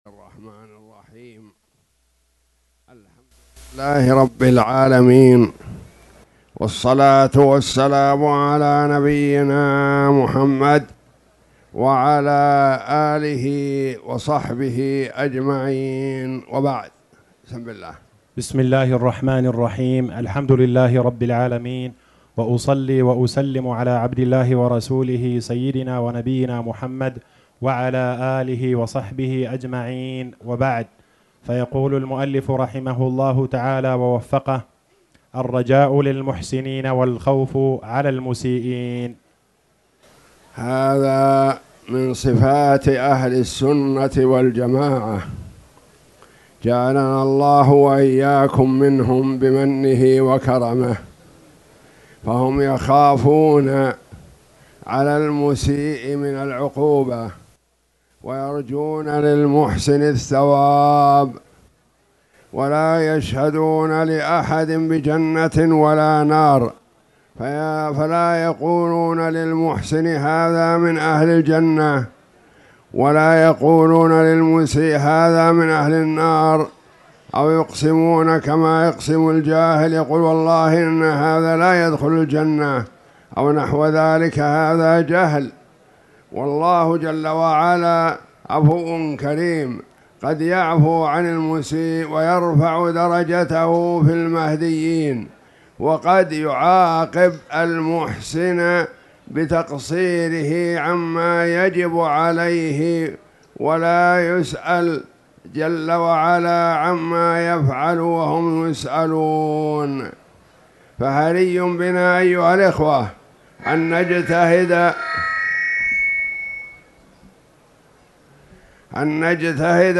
تاريخ النشر ١٥ رمضان ١٤٣٧ هـ المكان: المسجد الحرام الشيخ